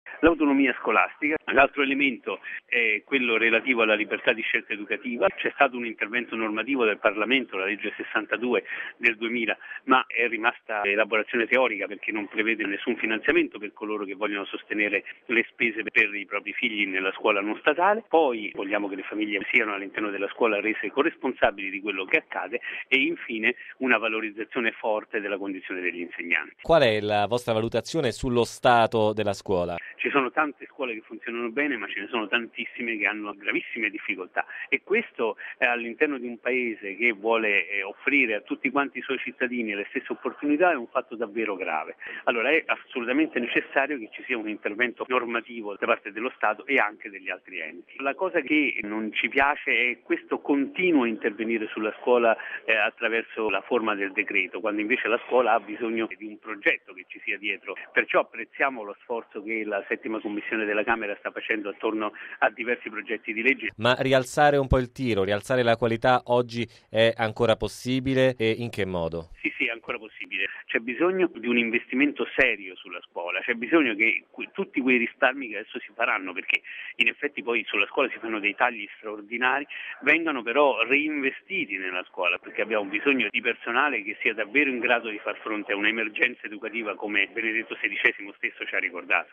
Autonomia scolastica, libertà di scelta educativa, corresponsabilità delle famiglie, maggiore considerazione verso gli insegnanti. Questi gli elementi irrinunciabili per una scuola di qualità secondo il Forum delle famiglie che questa mattina a Roma ha presentato una serie di proposte per un dibattito sulla riforma del settore. Quali le richieste?